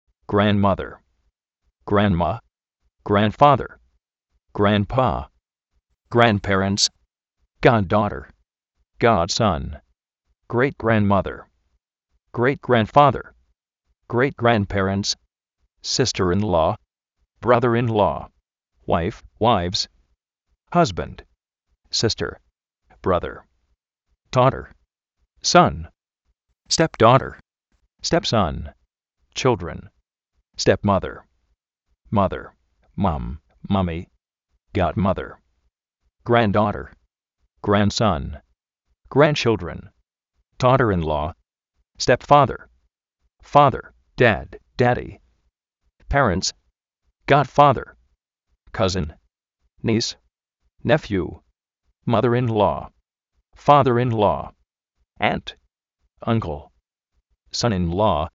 Vocabulario en ingles, diccionarios de ingles sonoros, con sonido, parlantes, curso de ingles gratis
gránd-máder
gránd-fáder grandpá
uáif, uáivs